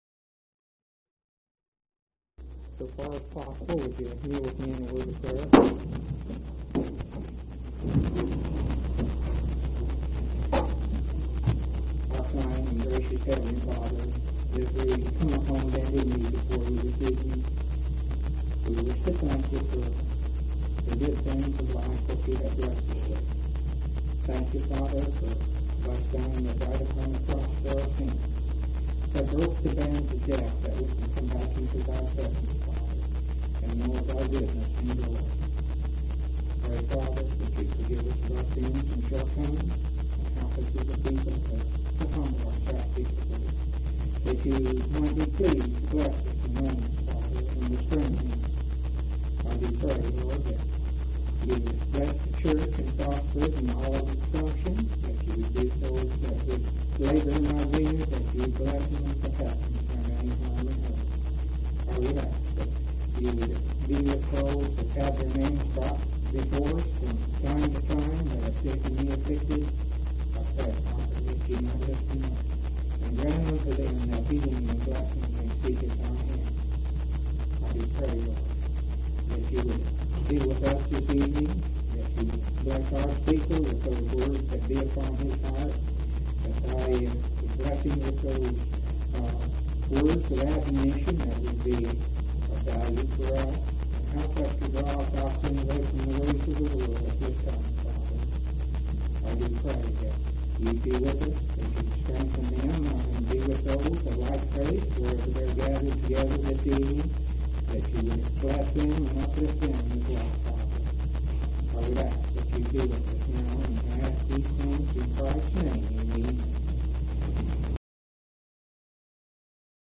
6/14/1998 Location: Colorado Reunion Event: Colorado Reunion